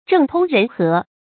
政通人和 zhèng tōng rén hé
政通人和发音
成语正音 通，不能读作“tònɡ”。